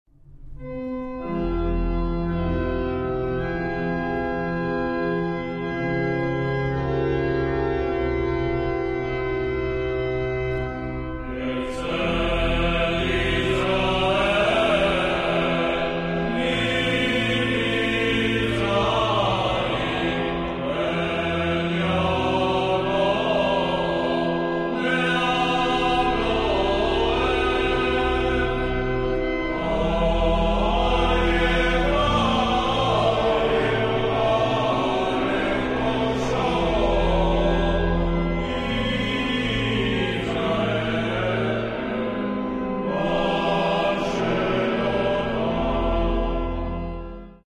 Musica tradizionale